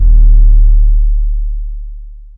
808 (Antidote) (1).wav